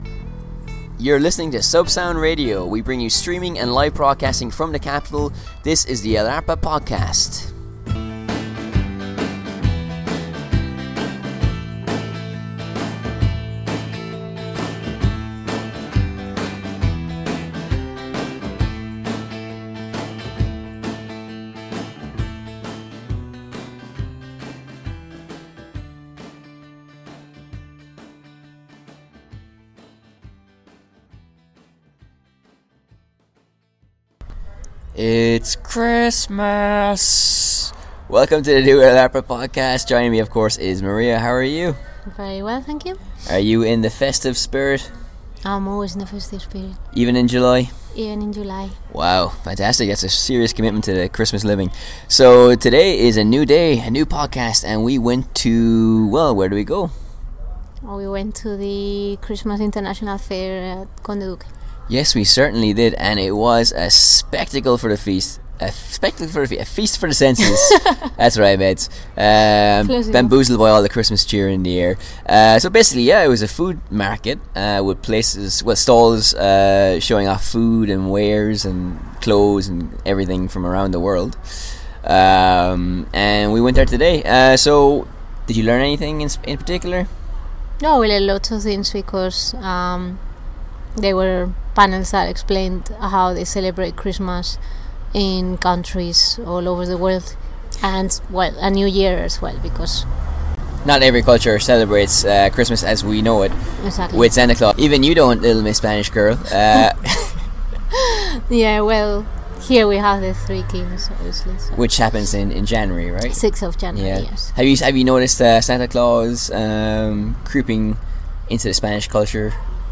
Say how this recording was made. Live from Madrid's Conde Duque bringing Christmas to your ears!